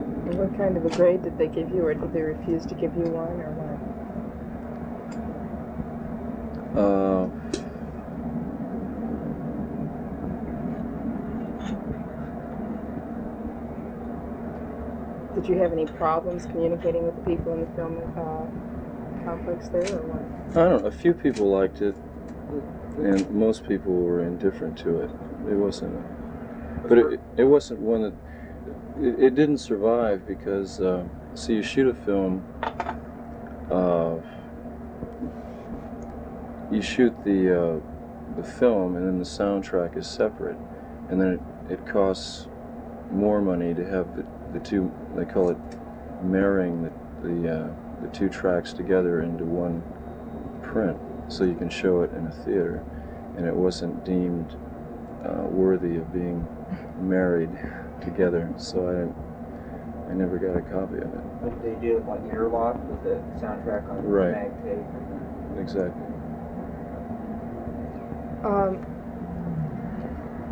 The Doors/The Lost Interview Tapes Featuring Jim Morrison - Volume Two The Circus Magazine Interview (Album)